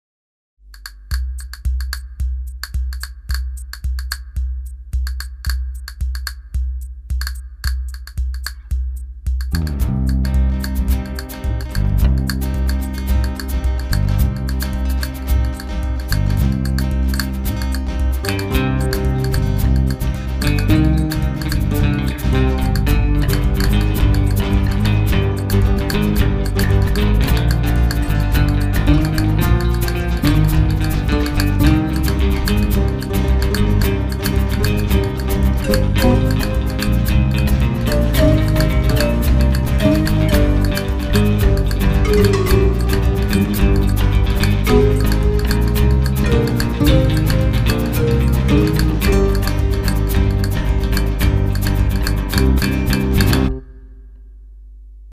Western, Mexican style?
La compo la plus originale.